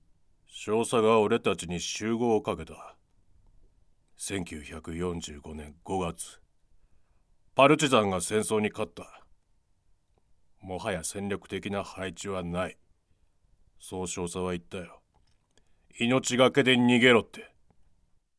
ボイスサンプル
セリフB